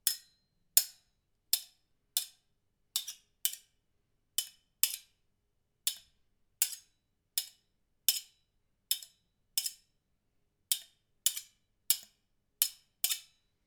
household
Kitchen Knife Scratches 2